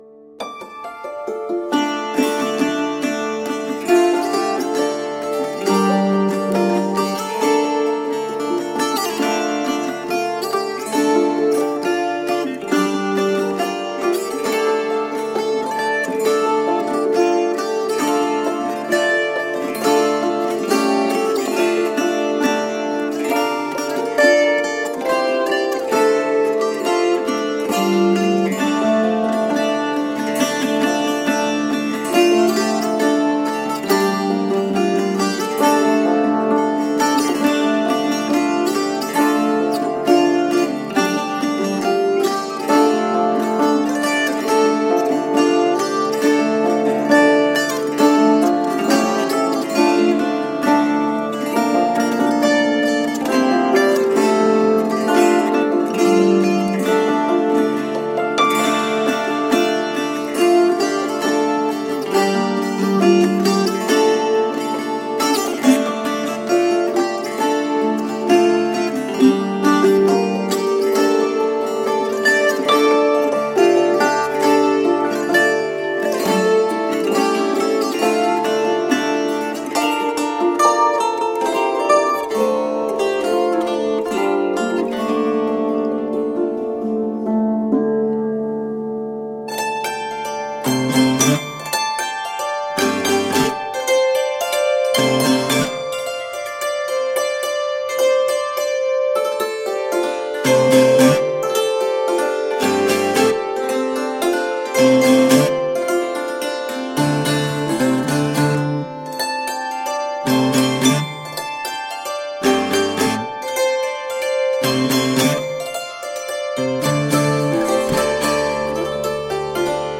Smile-inducing, toe-tapping folkgrass.